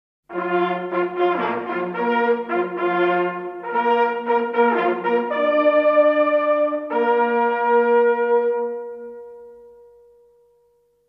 Die Jagdhornsignale sind die lauten Mitteilungen in der Jagd. Sie dienen einerseits den Ablauf einer Gruppenjagd zu koordinieren oder den erlegten Tieren mit den Jagdhörnern die letzte Ehre zu erweisen. Nachfolgend ein paar Jagdhornstücke.